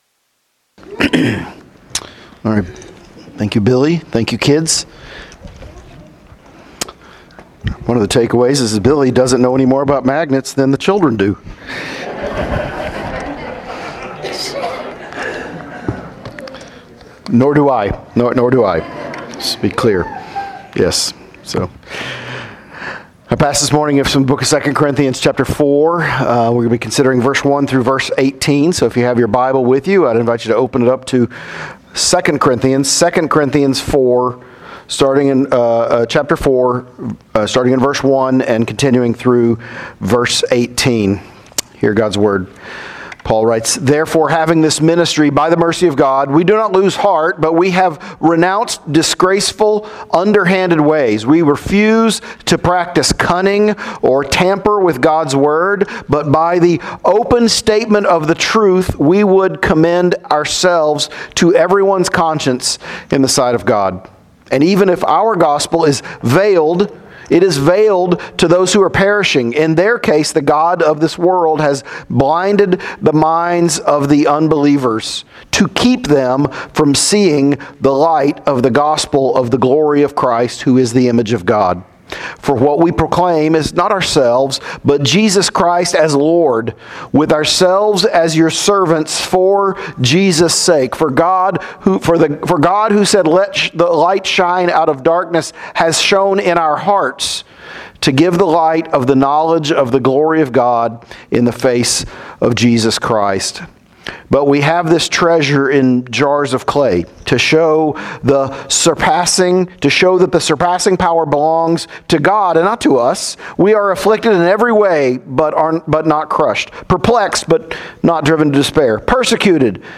Sermons | Hope Church PCA